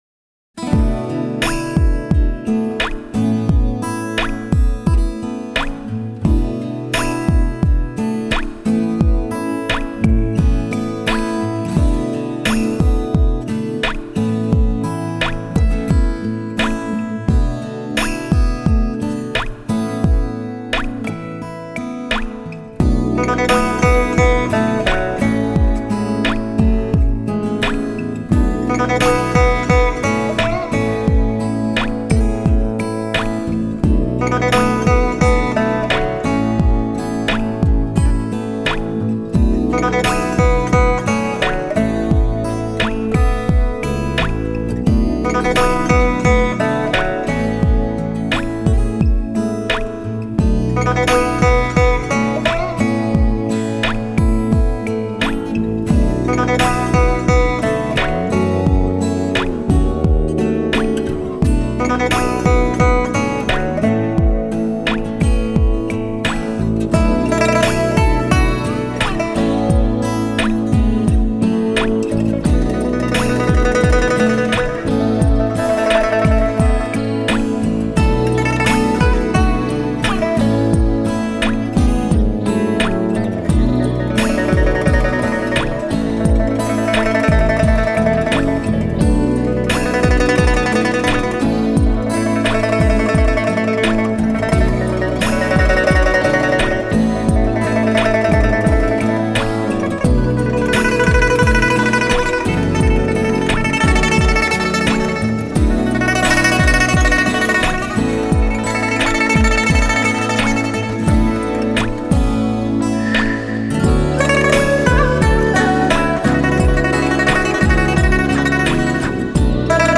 纯音雅乐
分类： 古典音乐、新世纪、纯音雅乐